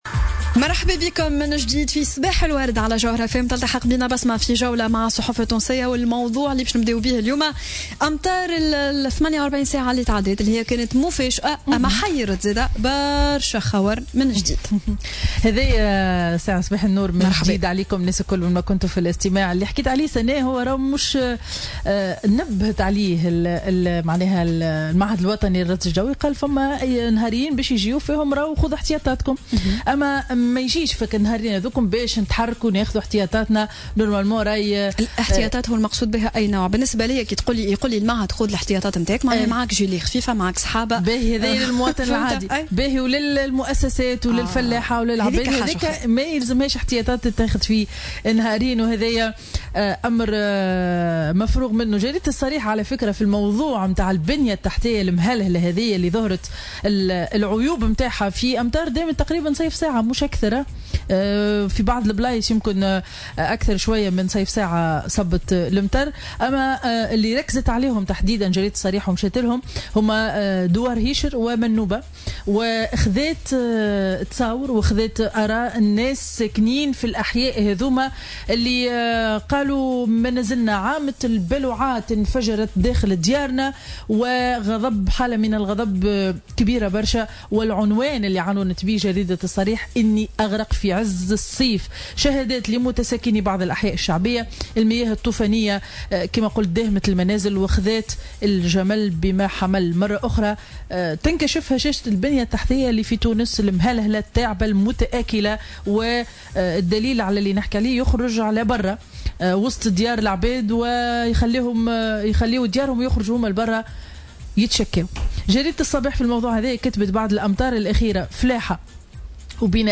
Revue de presse du mercredi 07 juin 2017